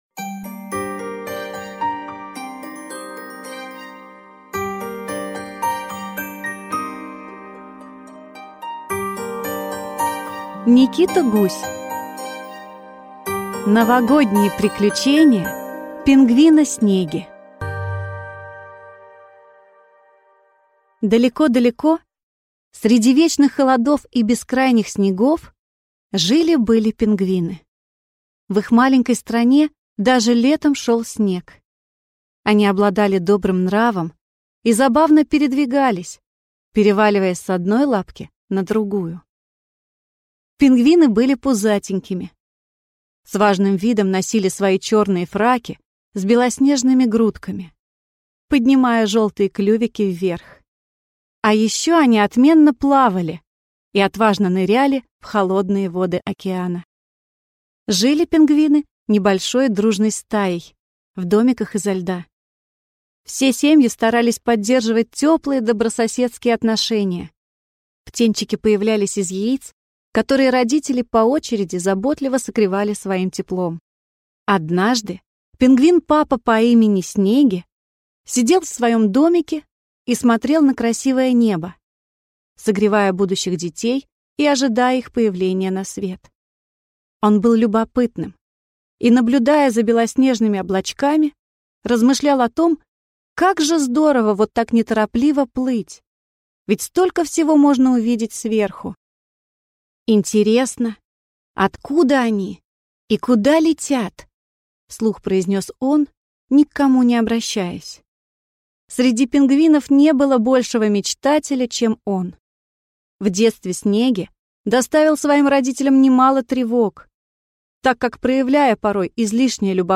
Аудиокнига Новогодние приключения пингвина Снеги | Библиотека аудиокниг